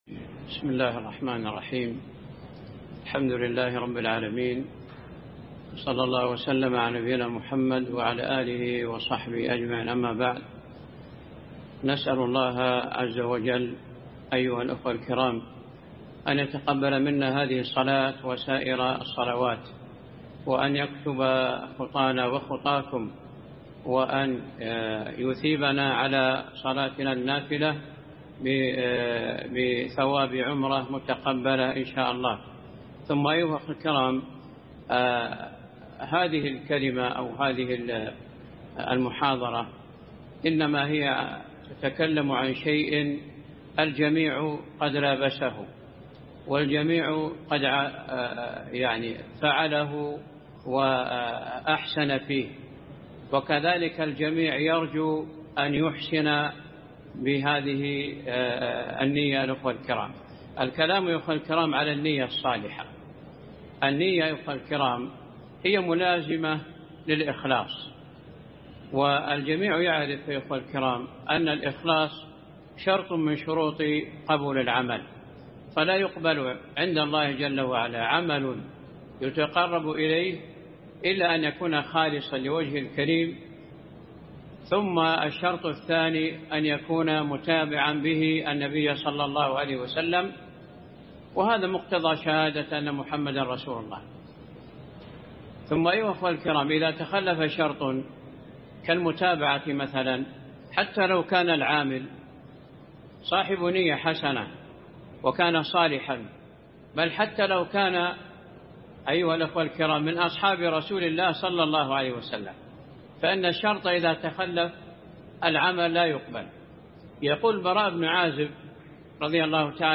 مسجد قباء سماع المحاضرة